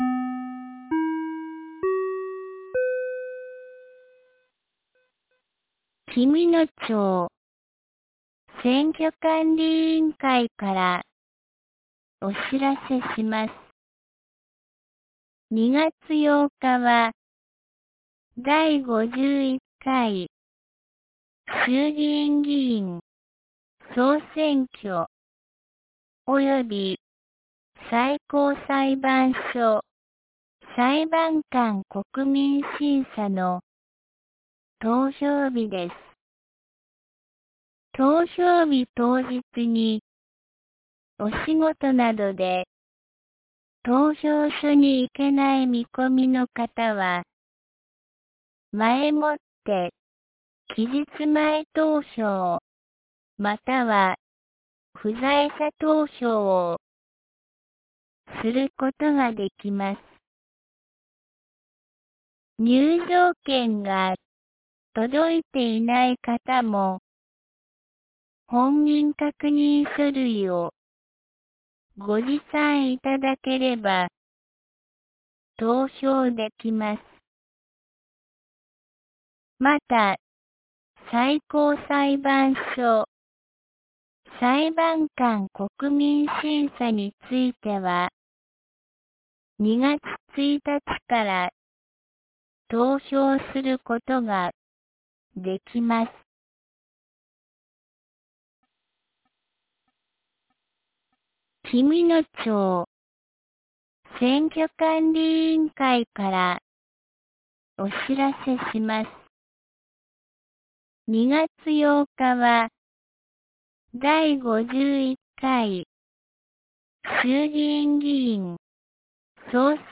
2026年01月28日 17時08分に、紀美野町より全地区へ放送がありました。